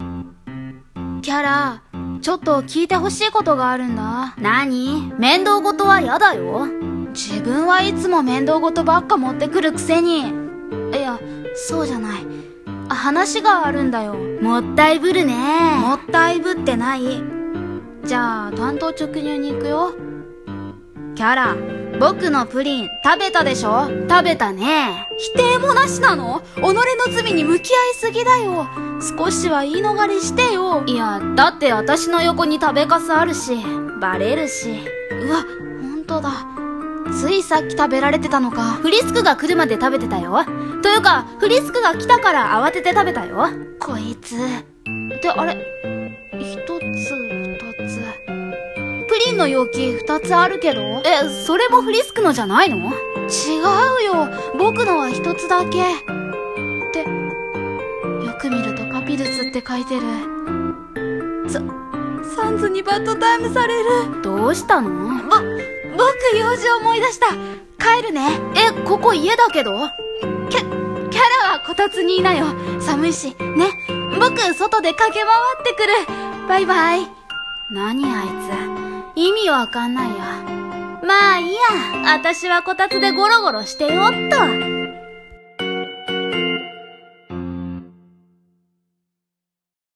声劇台本、冬の日の二人